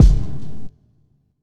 Medicated Kick 26.wav